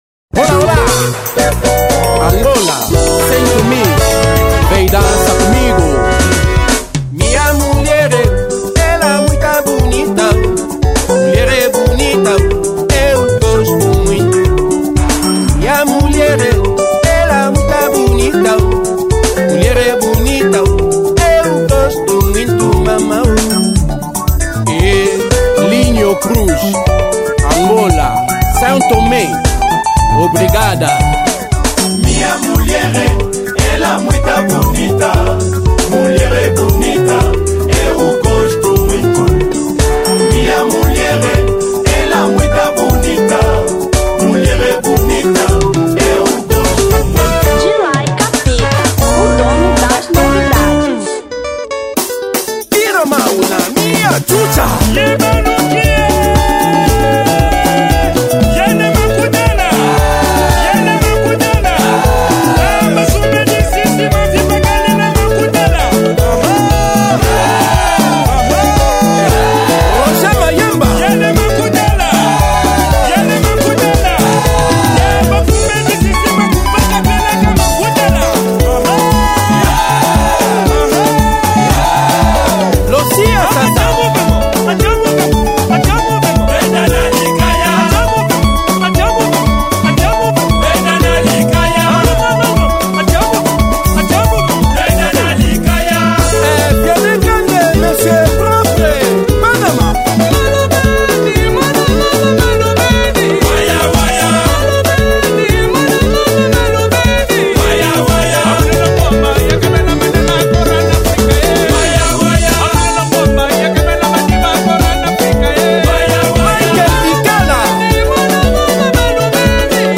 Rumba 2003